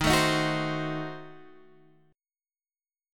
Ebdim7 chord